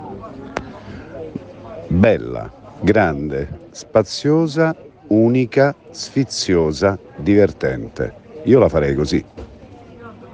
Abbiamo chiesto una recensione della Suite a Luca Ward, ascolta cosa ci ha risposto!